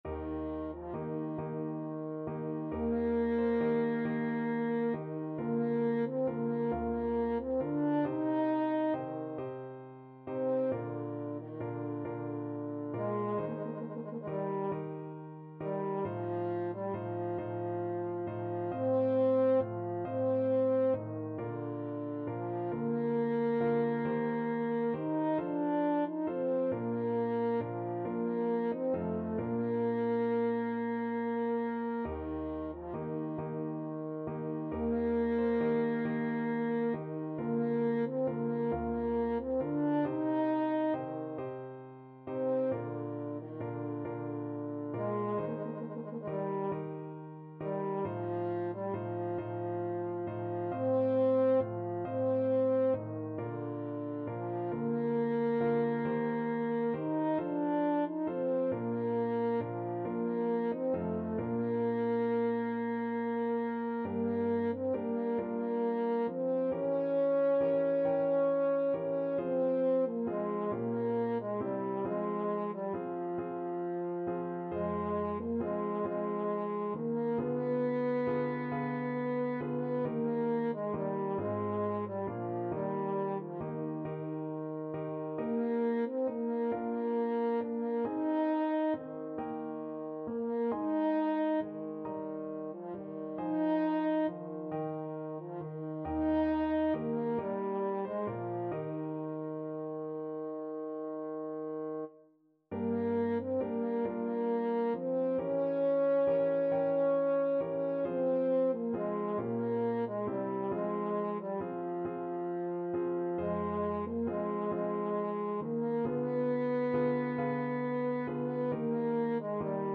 French Horn version
12/8 (View more 12/8 Music)
D4-Eb5
II: Larghetto cantabile .=45
Classical (View more Classical French Horn Music)